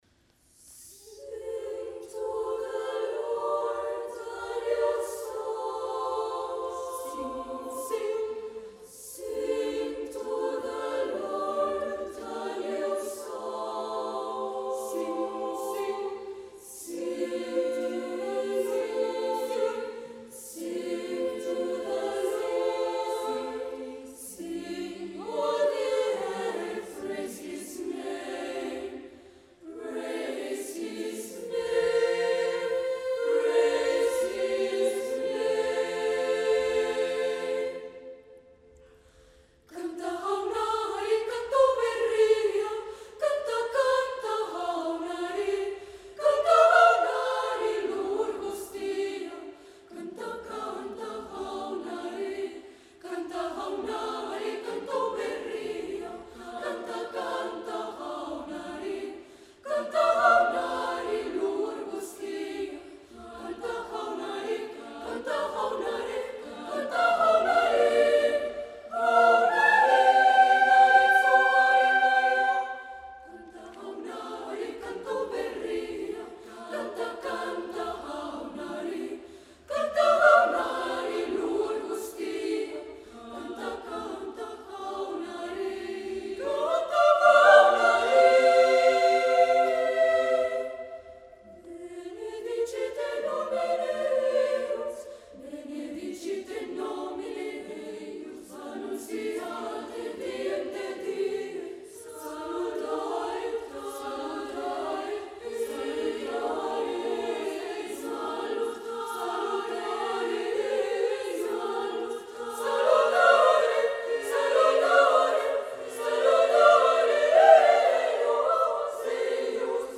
Ahir, tal i com ja es va anunciar fa dies a IFL, va tenir lloc a l’auditori AXA de l’Illa Diagonal el darrer concert de la temporada del Cor Vivaldi, el concert d’estiu que enguany comptava amb dos cors invitats arribats dels Estats Units (Piedmont East Bay Children’s Choir) i Dinamarca (Copenhagen girls choir) per interpretar a la segona part el Te Deum d’Albert Guinovart, mentre que a la primera, cadascuna de les formacions va fer un tast del seu repertori, sent el Vivaldi qui va compartir amb cadascun dels cors invitats, una obra en conjunt.
El Vivaldi va començar el concert una mica nerviós o poc concentrat, i tant en el “Sanctus” de Kirby Shaw com en el Alleluia de Randall Thompson vaig trobar a faltar aquella plenitud sonora en l’emissió prodigiosa que el caracteritza i aquell perfecte equilibri que el distinngeix, només va ser en el magnífic Cantate Domino  de Josu Elberdin que vaig reconèixer el Vivaldi de les grans ocasions, mostrant aquella emissió adulta i compacte que costa tant entendre veient l’edat i l’alçada de la majoria dels seus components